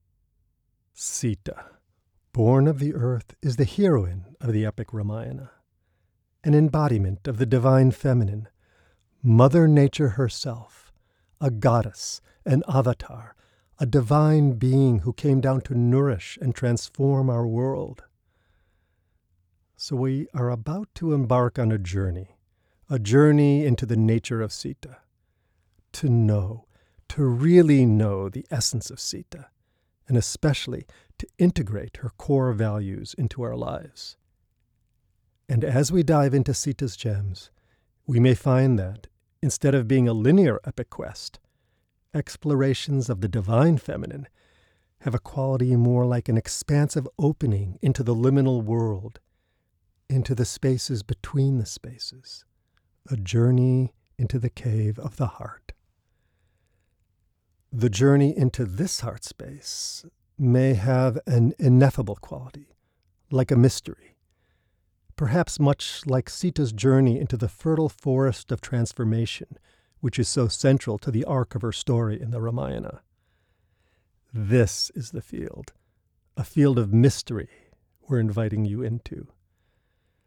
part audiobook, part guided course